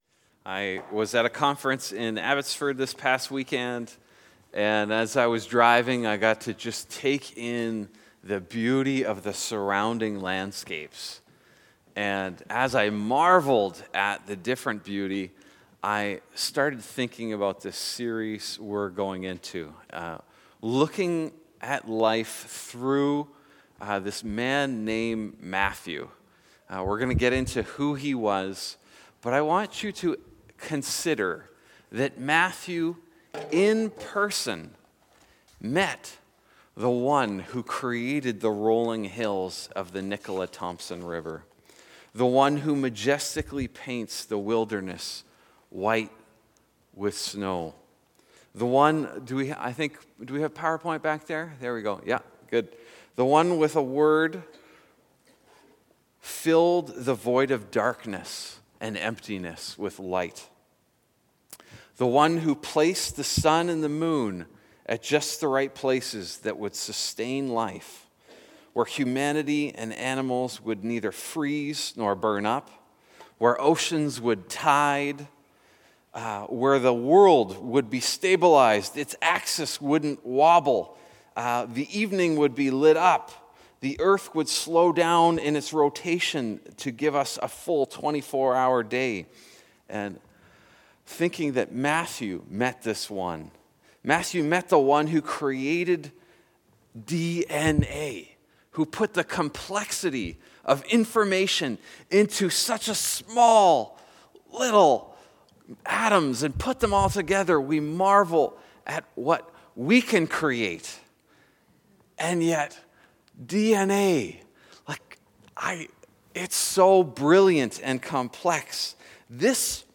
Sermons | Northstar Church